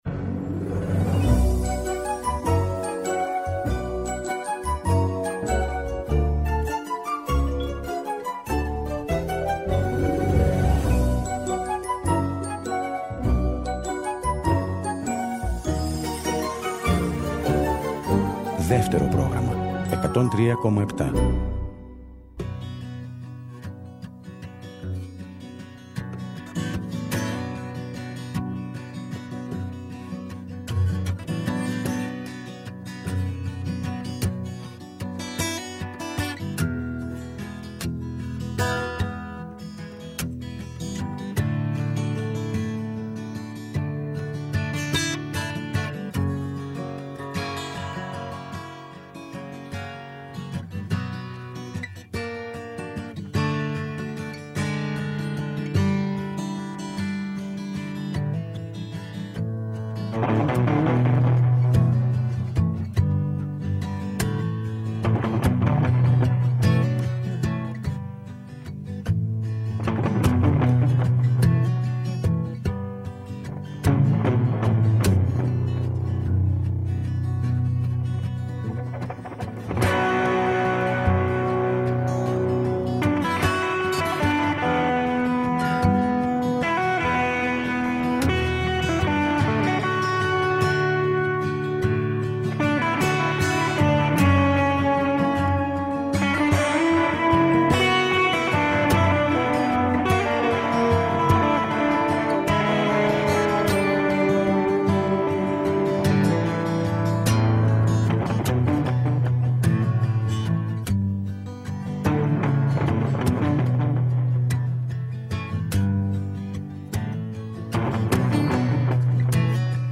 “Ροκ συναναστροφές” με το …”ροκ” όχι μόνο ως μουσική φόρμα, αλλά περισσότερο ως στάση ζωής. Αγαπημένοι δημιουργοί και ερμηνευτές αλλά και νέες προτάσεις, αφιερώματα και συνεντεύξεις, ο κινηματογράφος, οι μουσικές και τα τραγούδια του.